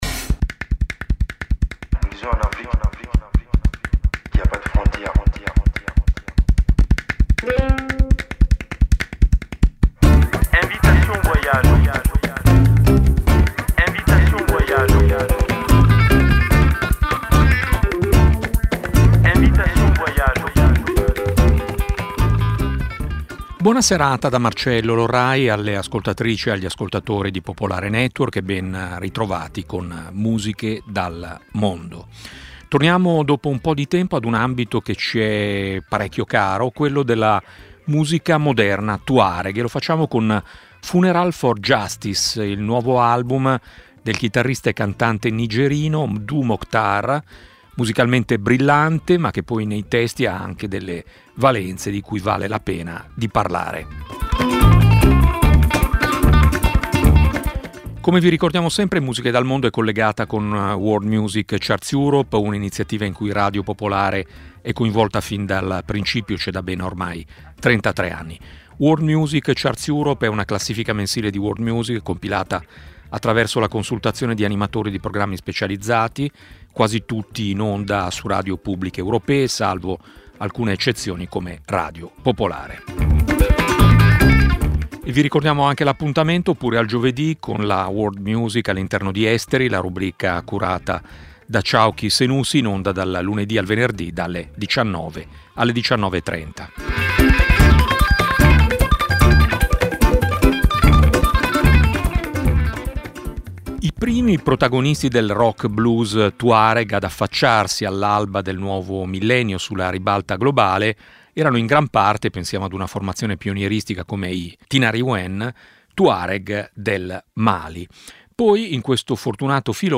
Musiche dal mondo è una trasmissione di Radio Popolare dedicata alla world music, nata ben prima che l'espressione diventasse internazionale.
Un'ampia varietà musicale, dalle fanfare macedoni al canto siberiano, promuovendo la biodiversità musicale.